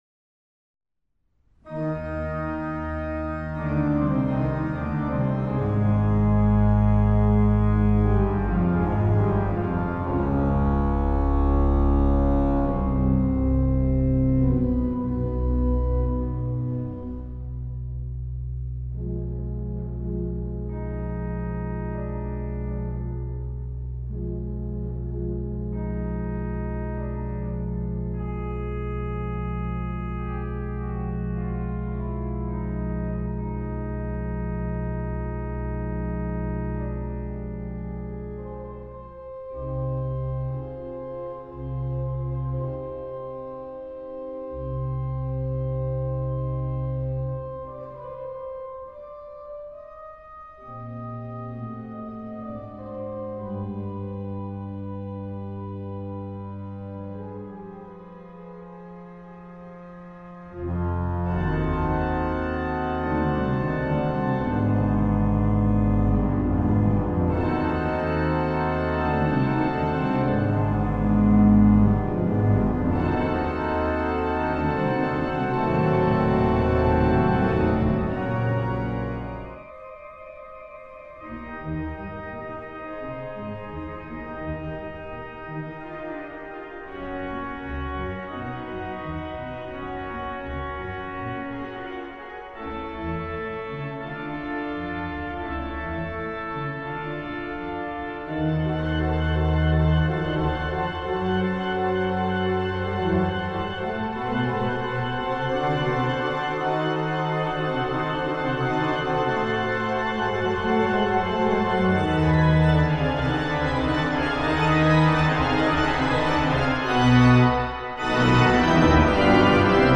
Transcription for organ
poéme symphonique